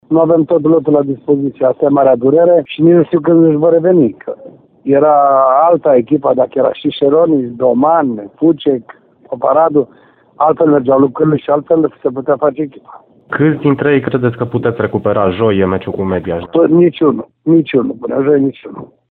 Antrenorul Ionuț Popa spune însă că nu sunt șanse pentru recuperarea jucătorilor absenți și astăzi din cauze medicale: